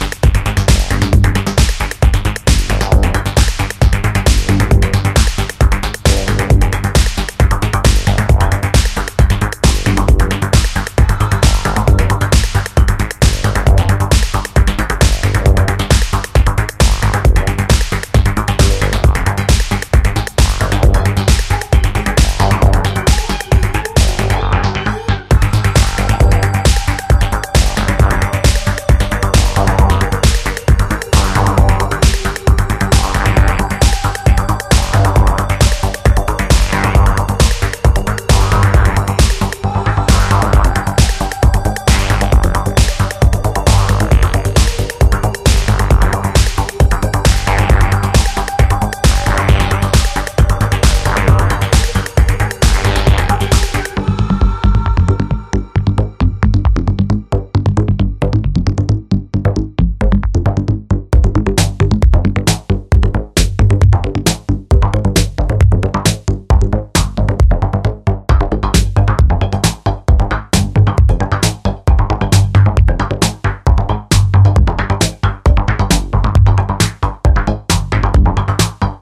a complete pure analog ep